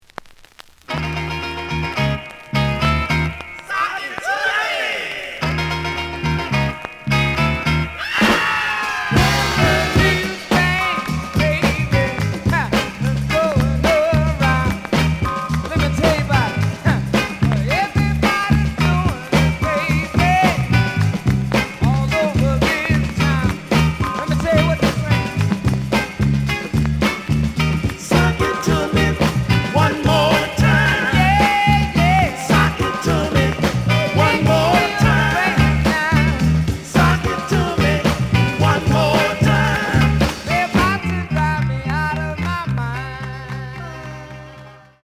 The audio sample is recorded from the actual item.
●Genre: Funk, 60's Funk